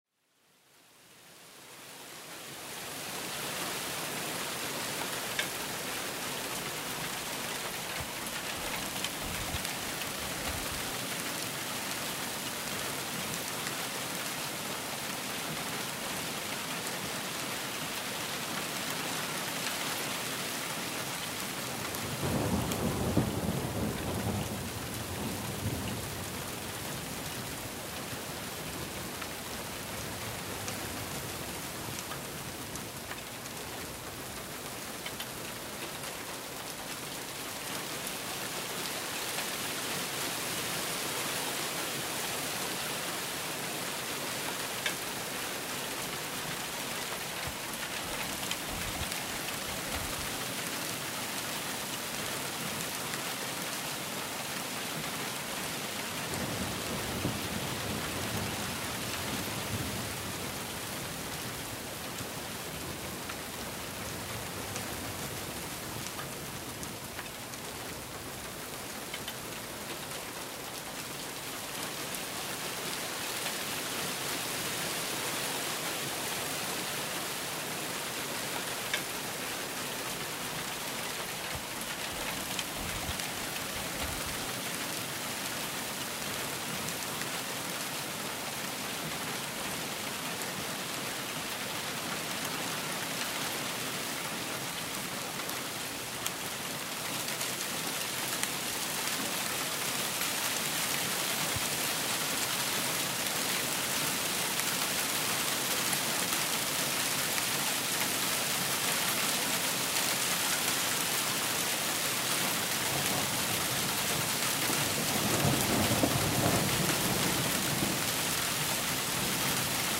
na_regn_aska_02.mp3